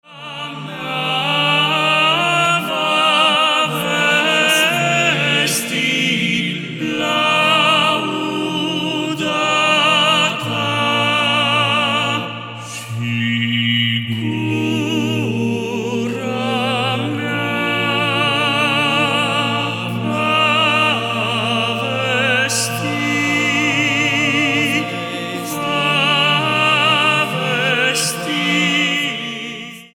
four male voices
Byzantine Orthodox Songs